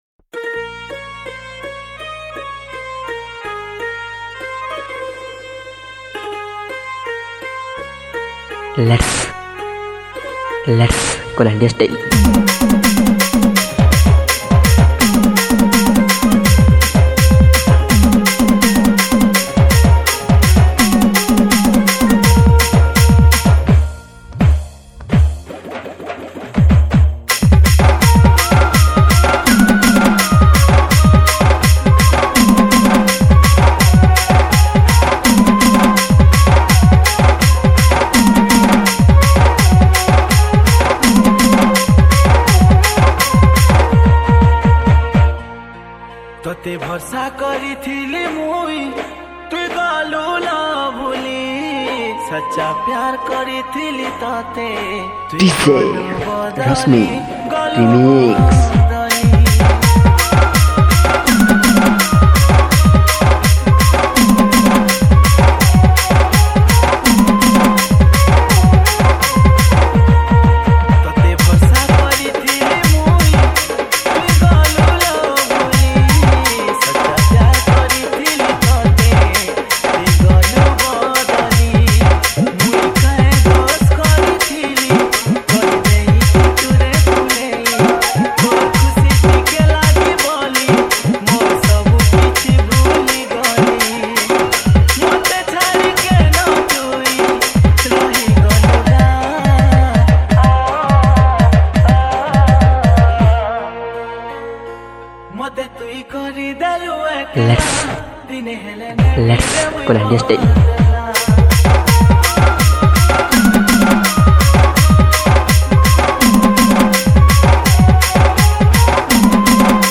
SAMBALPURI SAD DJ REMIX